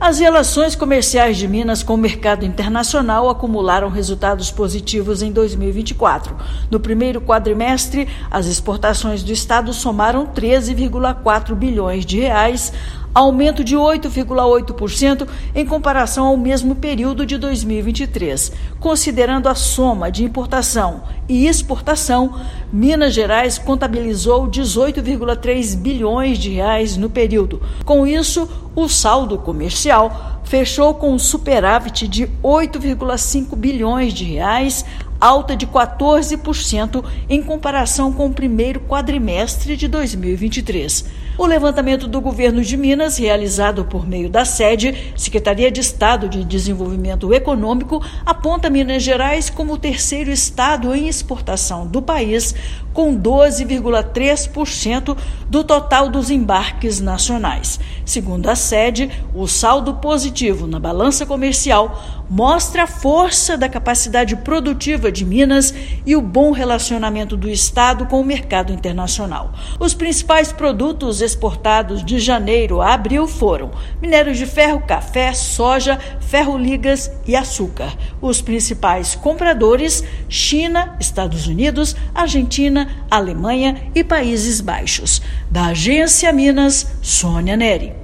Estado é o 3º principal exportador do Brasil e atingiu superávit na balança de US$ 8,5 bilhões, um aumento de 14% no 1º quadrimestre. Ouça matéria de rádio.